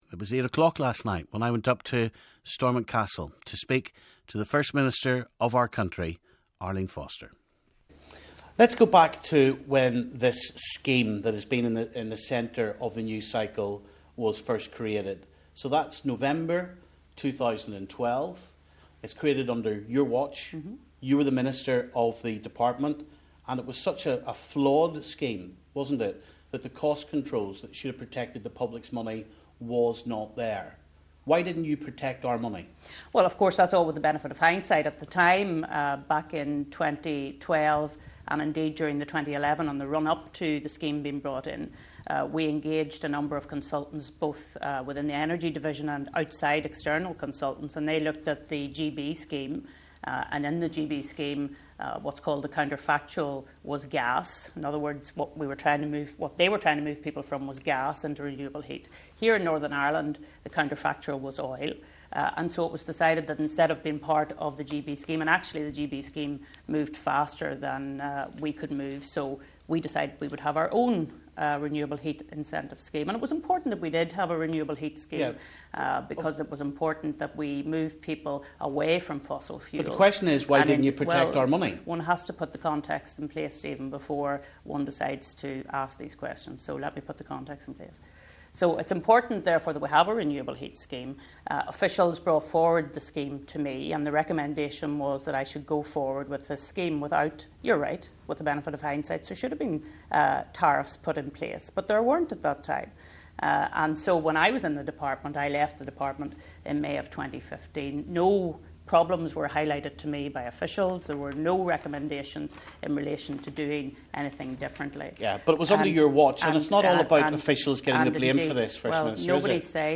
We bring you a longer version of the Arlene Foster interview.